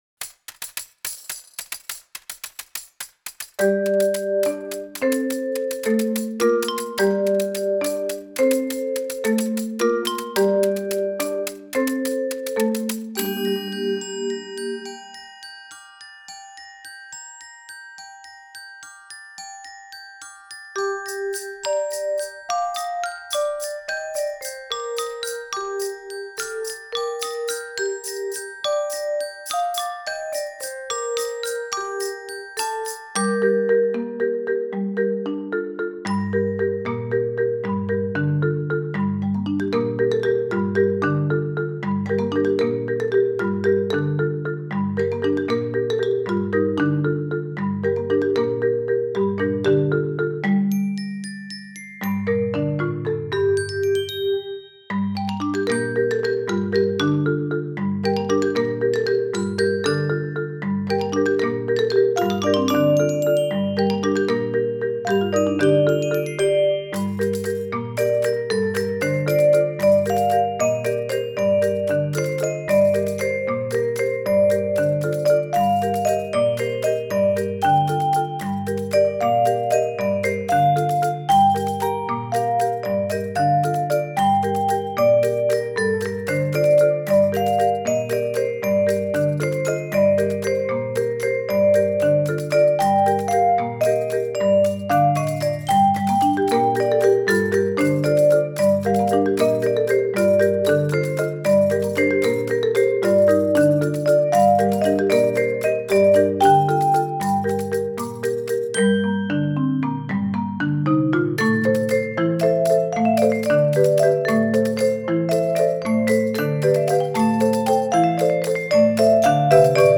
Casi una Cueca para percusión